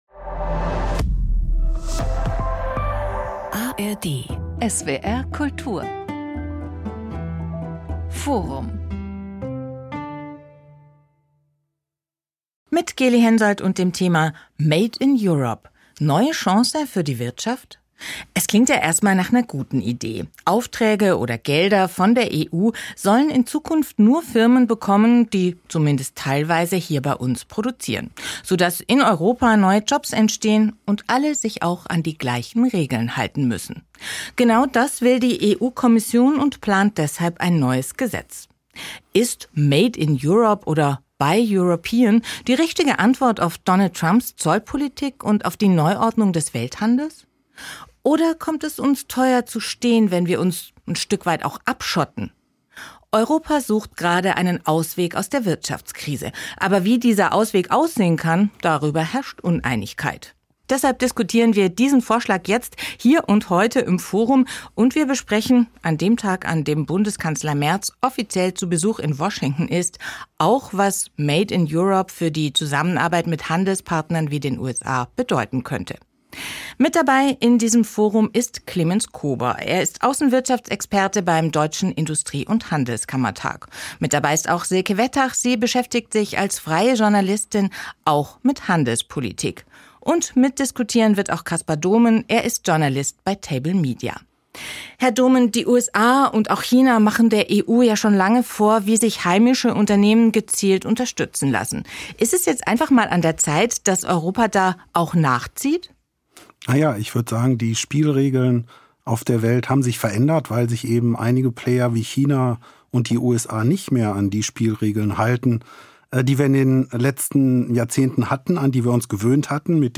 freie Journalistin Mehr